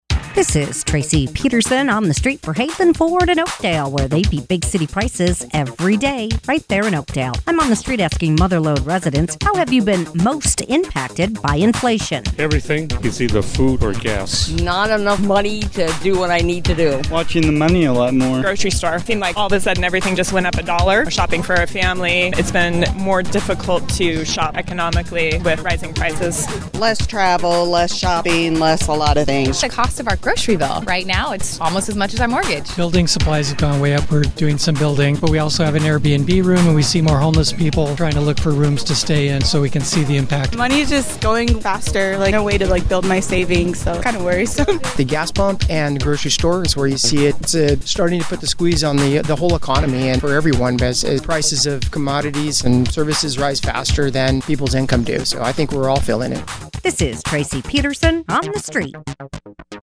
asks Mother Lode residents, “How have you been most impacted by inflation?”